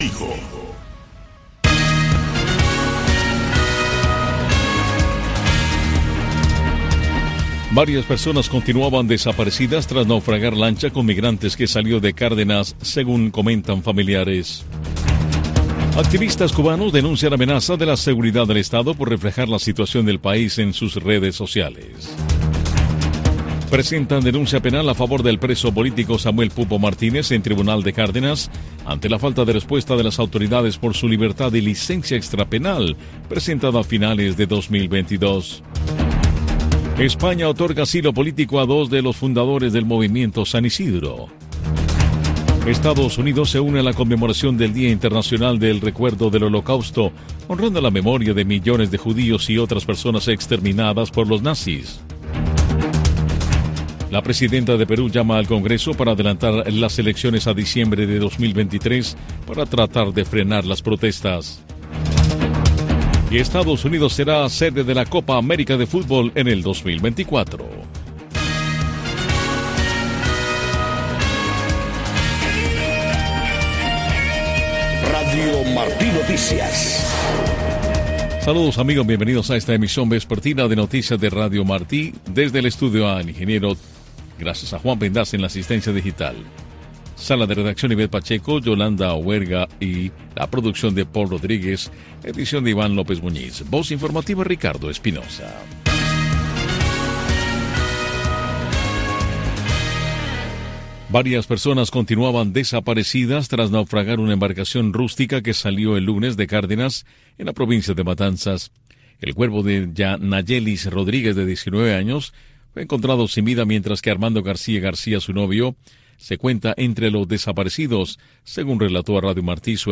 Noticiero de Radio Martí 5:00 PM | Primera media hora